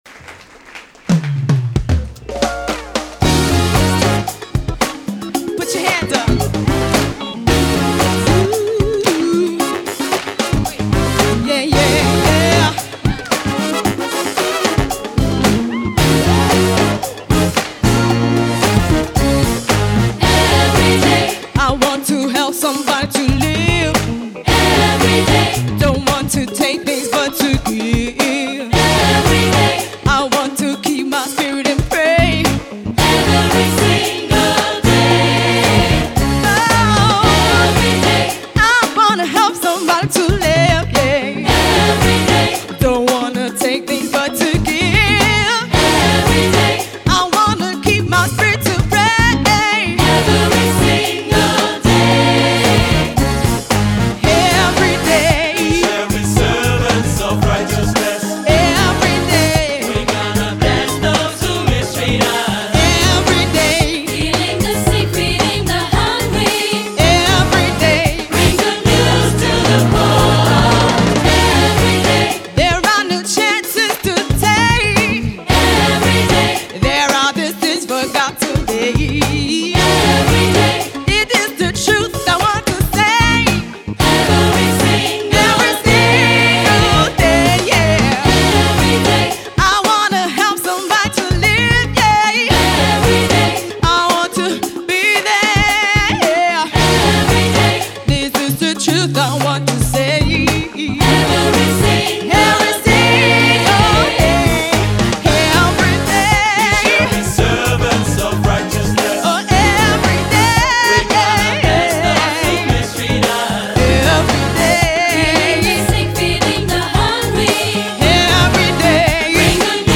• SAB, auch SSA, opt. Solo + Piano
GOSPELNOTEN